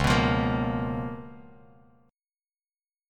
Db13 Chord
Listen to Db13 strummed